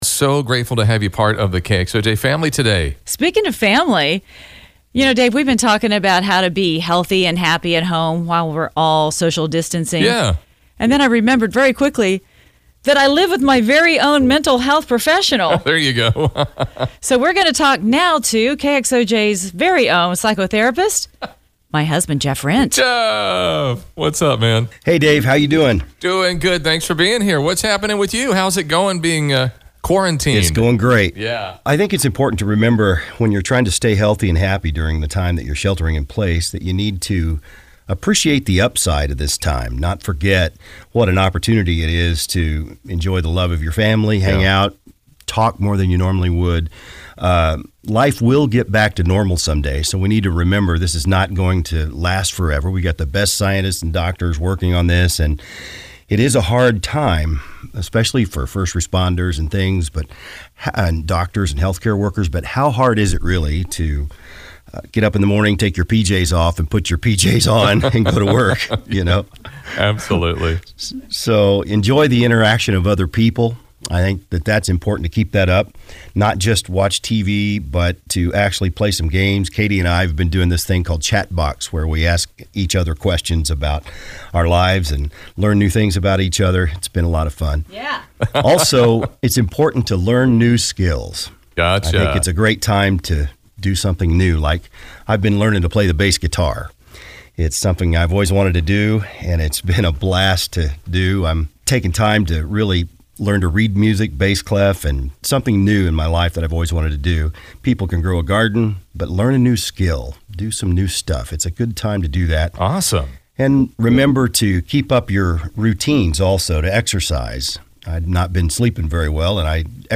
A mental health expert shares how to stay positive with all that’s going on